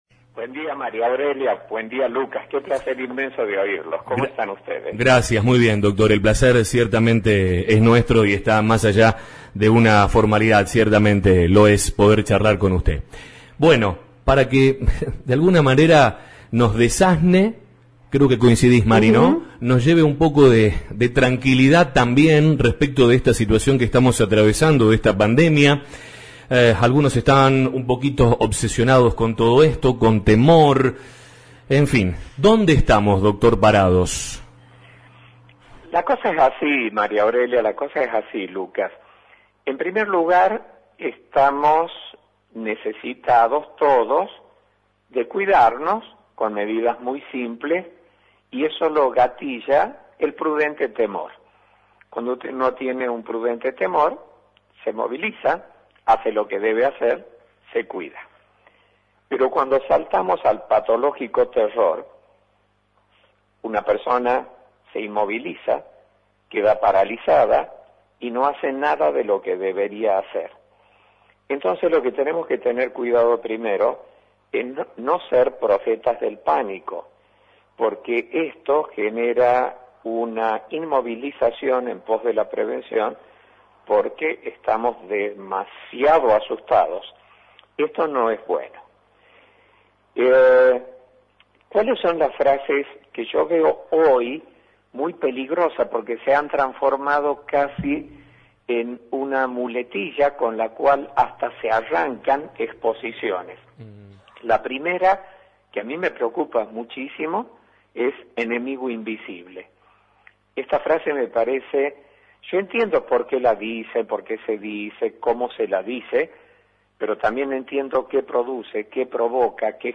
En una didáctica exposición radial de 40 minutos, el tucumano hizo una clara explicación sobre el virus, la manera en que lo enfrentamos, los miedos y las mejores acciones para evitar su propagación, entre otros puntos.